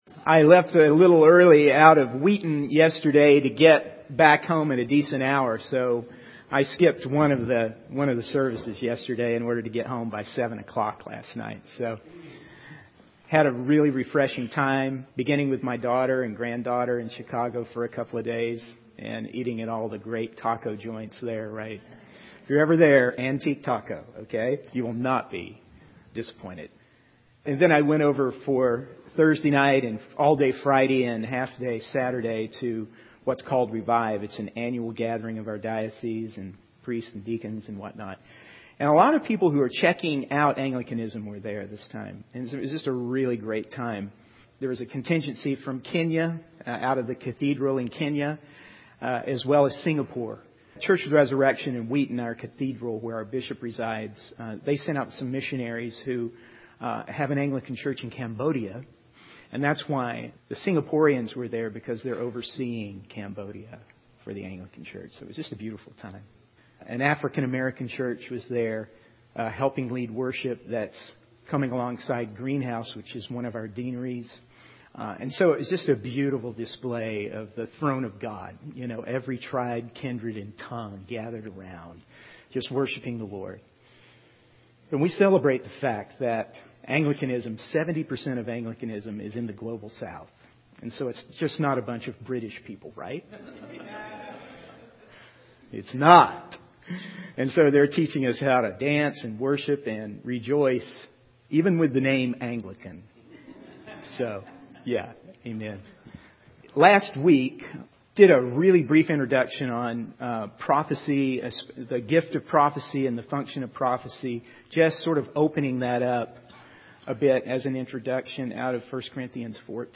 In this sermon, the speaker discusses the importance of hearing and receiving the word of the Lord. They share testimonies from three individuals who have experienced the prophetic word in their lives. The speaker emphasizes the need for unity and encouragement among believers, citing the example of the day of Pentecost. They also highlight the power of prophetic words in breaking spiritual strongholds and bringing freedom.